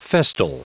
Prononciation du mot festal en anglais (fichier audio)